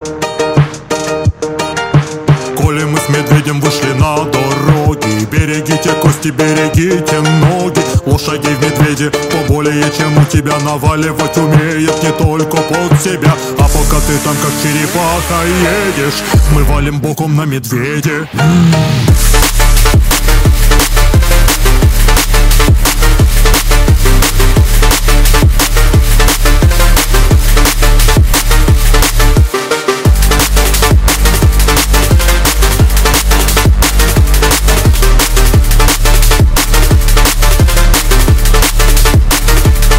дабстеп , нейрофанк , драм энд бейс